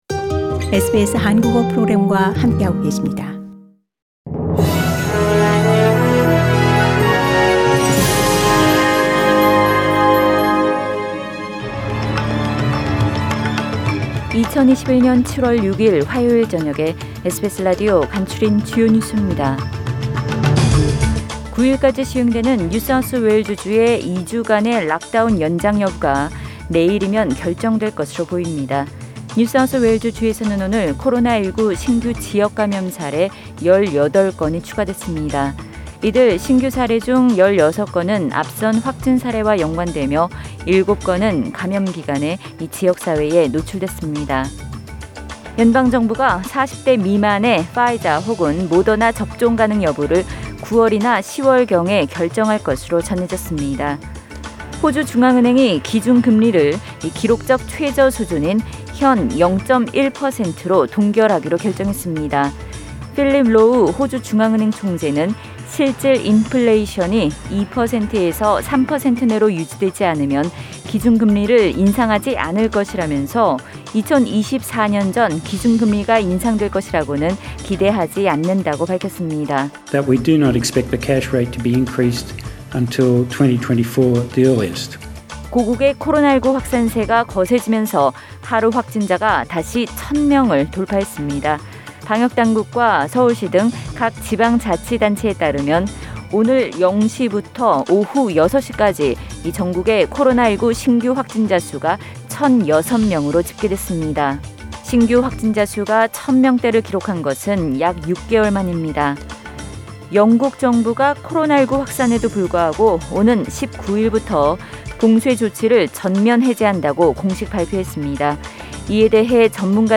SBS News Outlines…2021년 7월 6일 저녁 주요 뉴스